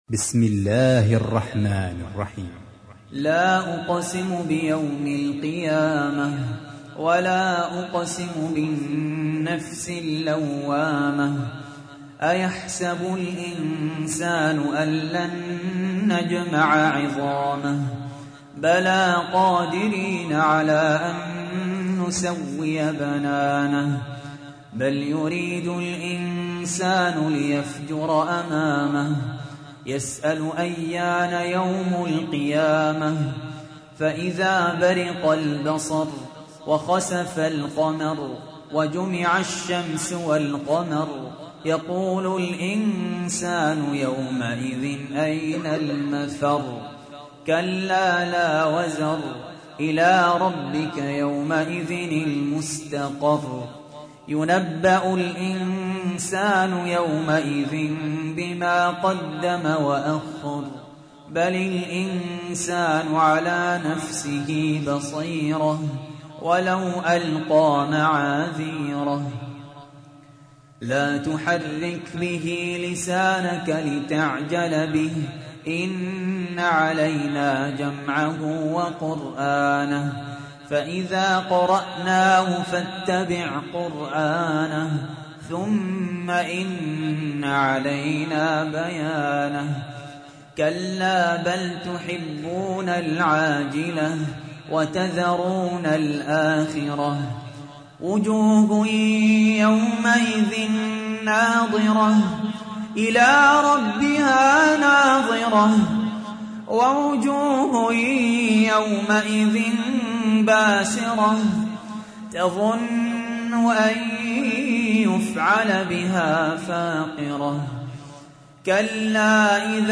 تحميل : 75. سورة القيامة / القارئ سهل ياسين / القرآن الكريم / موقع يا حسين